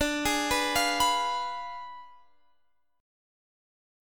Listen to Ddim7 strummed